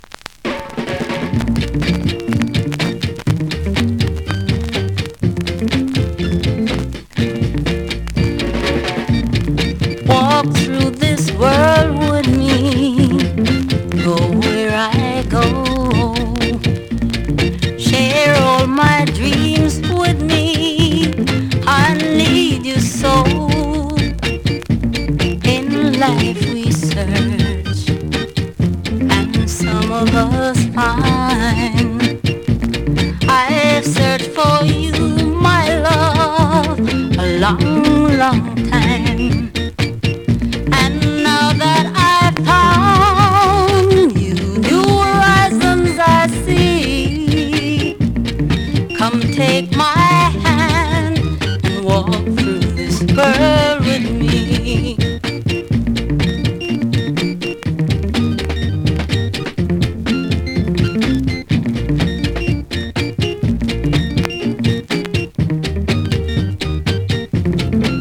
SKA〜REGGAE
スリキズ、ノイズそこそこありますが